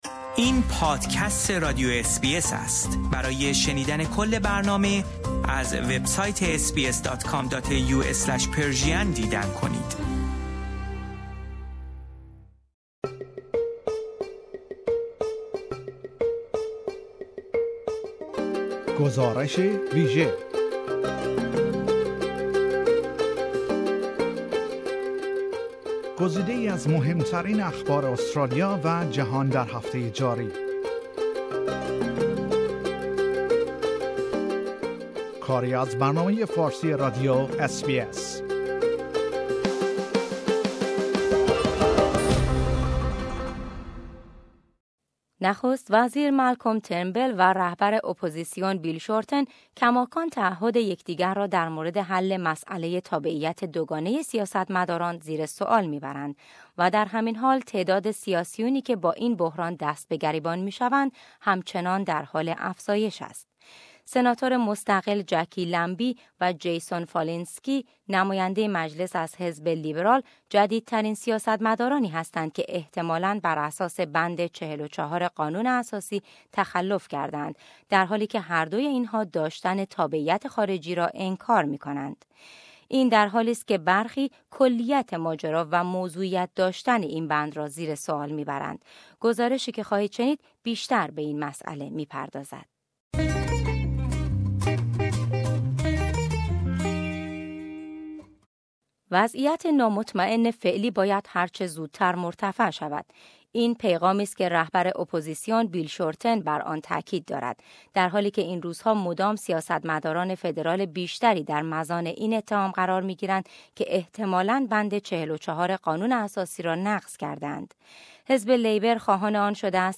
گزارشی که خواهید شنید، بیشتر به این مساله می پردازد.